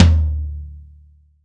ROCK LO-TOM.WAV